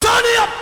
Waves Vox.wav